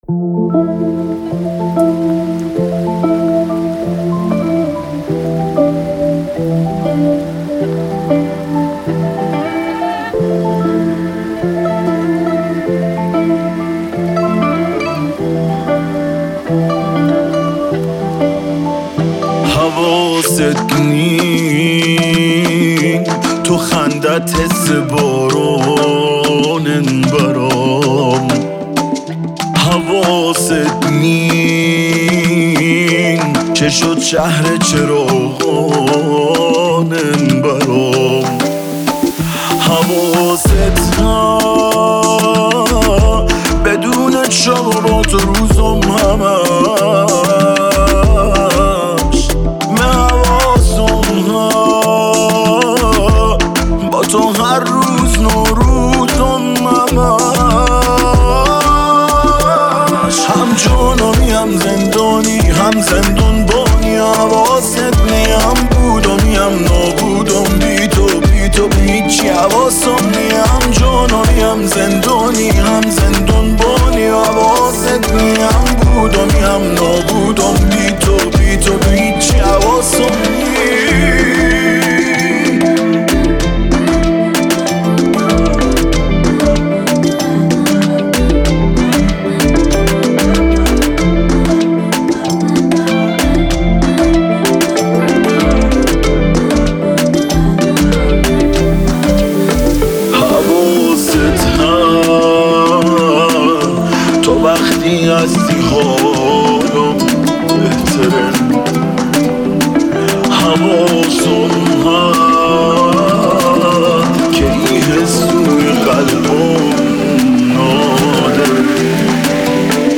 پاپ شاد عاشقانه بندری